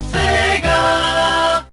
Sega Megadrive-Genesis Startup.wav